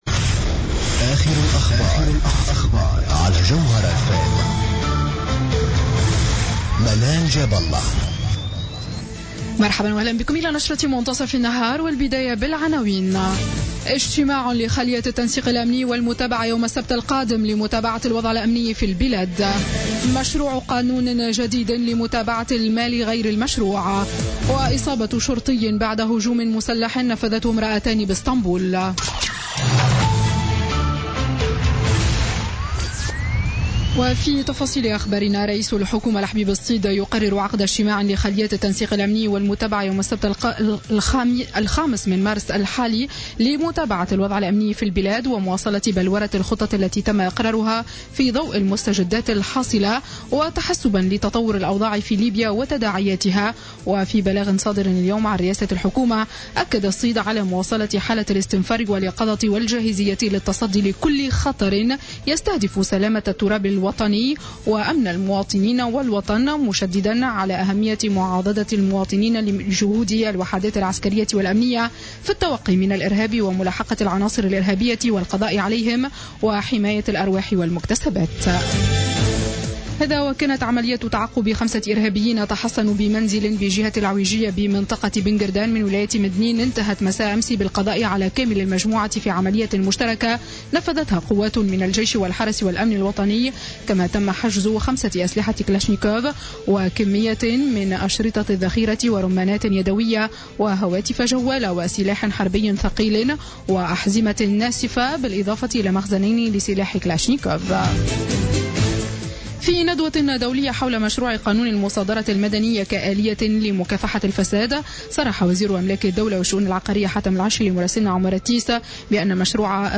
Journal Info 12h00 du jeudi 3 mars 2016